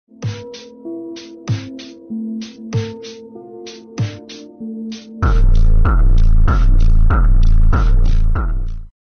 phonk